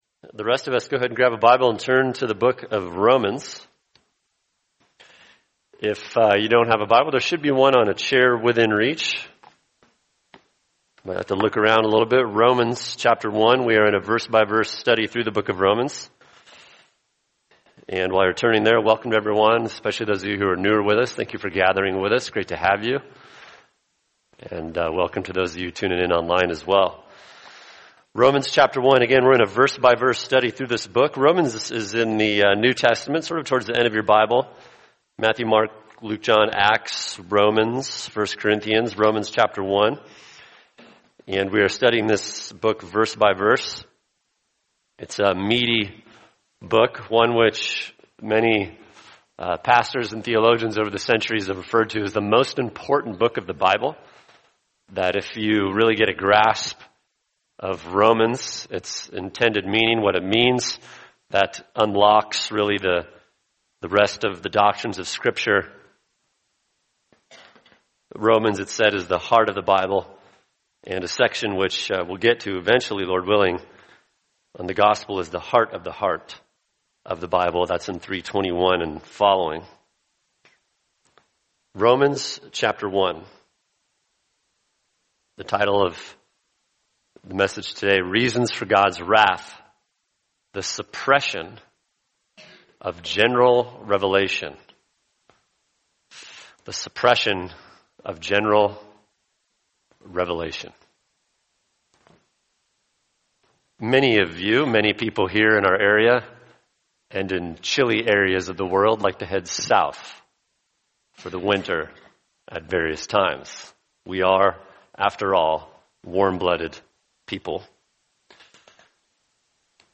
[sermon] Romans 1:19-20 Reasons for God’s Wrath: The Suppression of General Revelation | Cornerstone Church - Jackson Hole